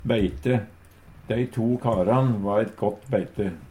beite - Numedalsmål (en-US)
DIALEKTORD PÅ NORMERT NORSK beite arbeidslag Eintal ubunde Eintal bunde Fleirtal ubunde Fleirtal bunde Eksempel på bruk Dei to karan va eit gøtt beite Hør på dette ordet Ordklasse: Substantiv hankjønn Attende til søk